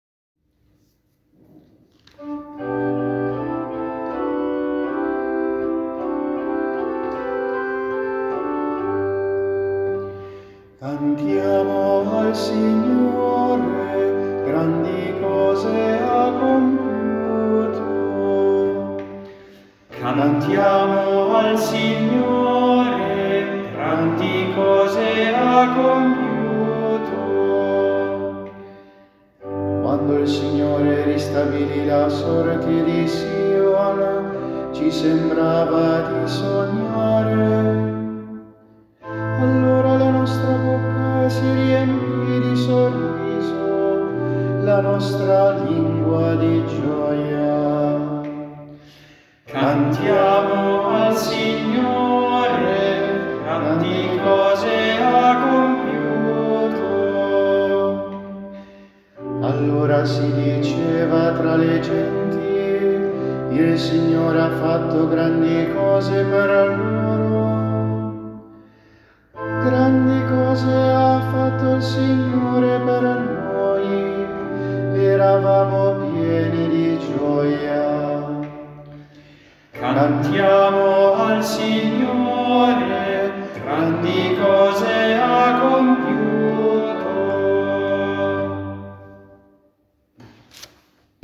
Esecuzione decanato Vigentino